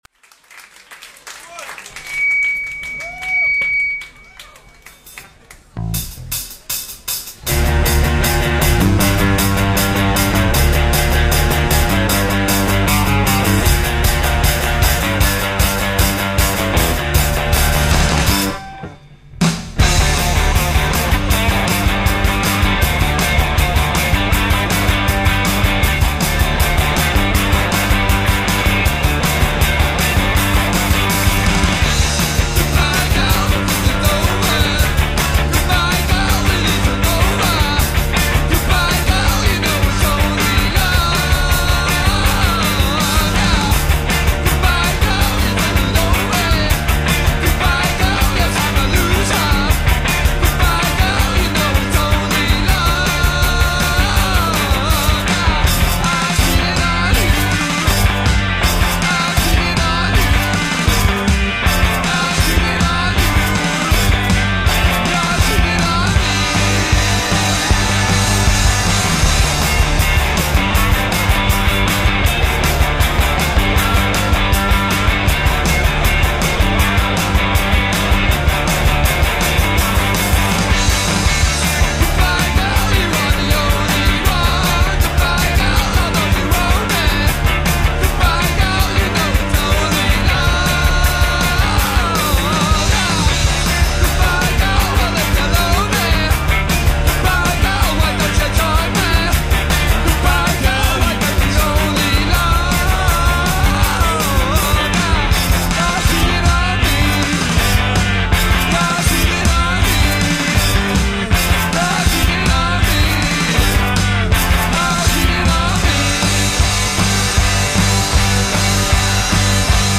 From a concert performed in Liverpool in 2003